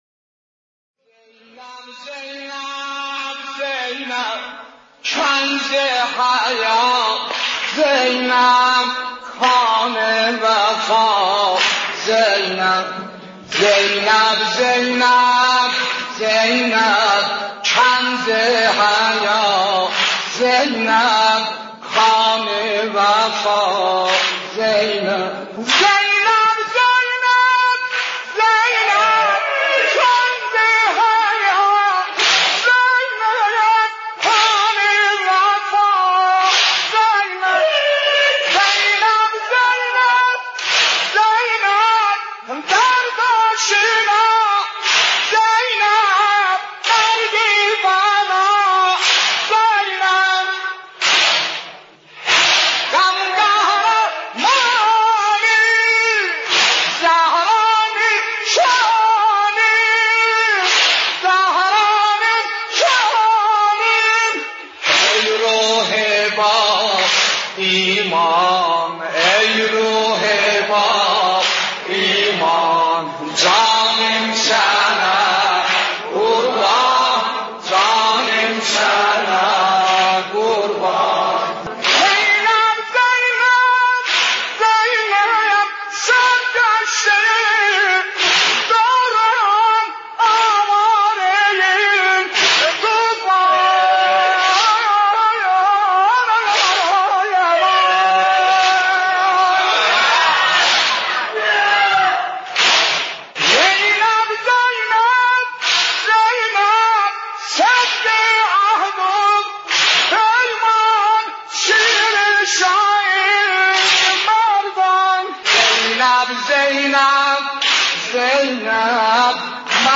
نوحه آذری «زینب، زینب» با صدای سلیم موذن زاده/دانلود | پایگاه خبری تحلیلی بهارانه